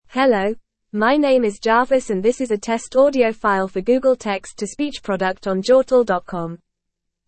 English Female uk tts